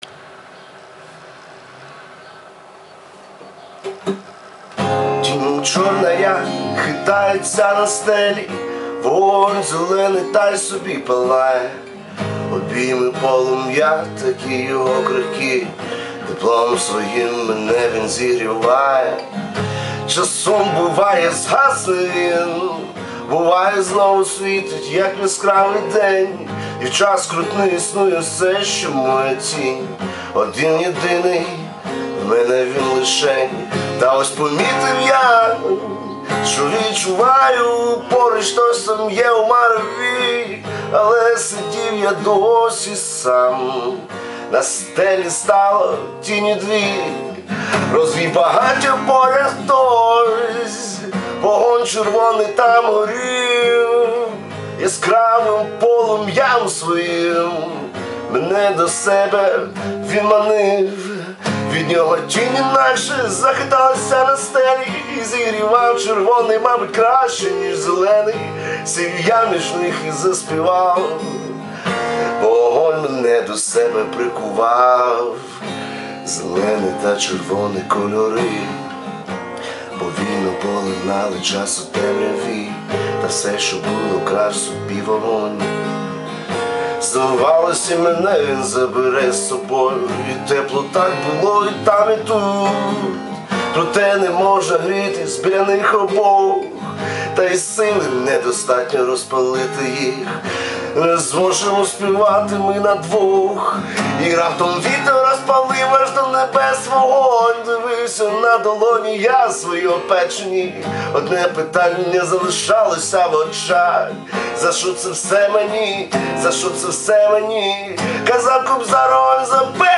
ТИП: Пісня
СТИЛЬОВІ ЖАНРИ: Ліричний
Пташечки співають!))